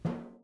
timpsnaretenor_f.wav